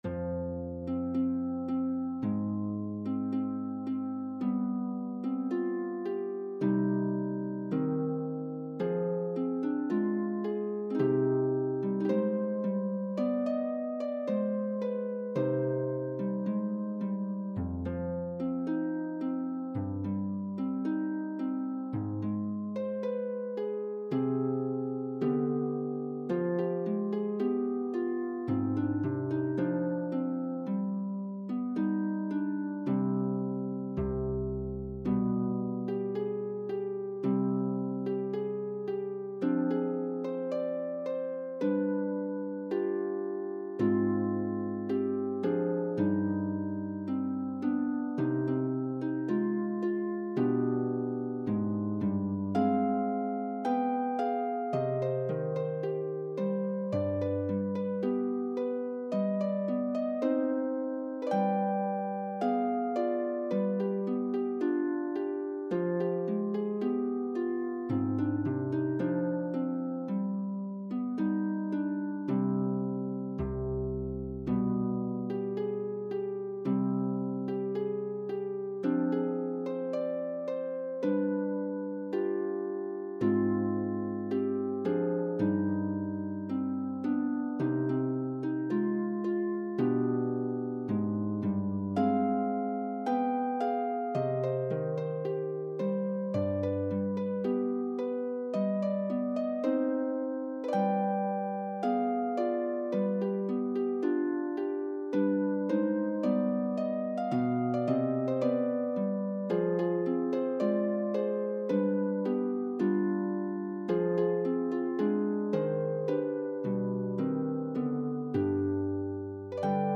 for solo lever or pedal harp